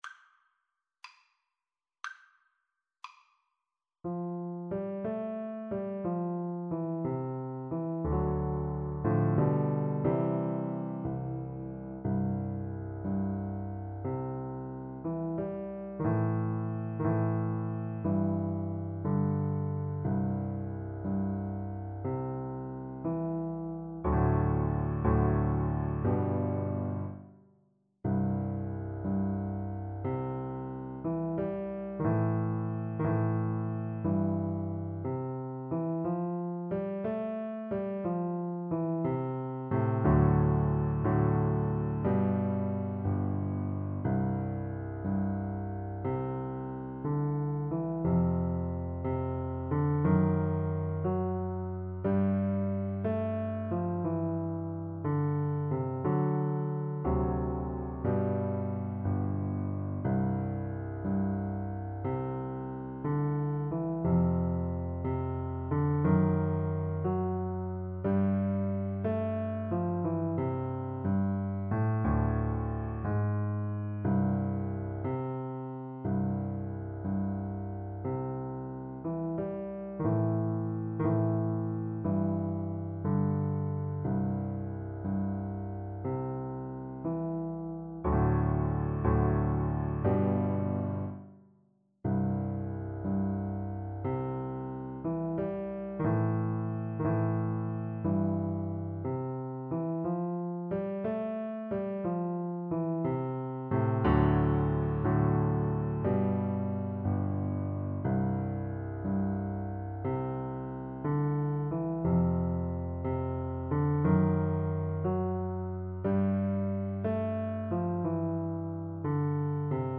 6/8 (View more 6/8 Music)
Gently and with expression .
Piano Duet  (View more Intermediate Piano Duet Music)
Traditional (View more Traditional Piano Duet Music)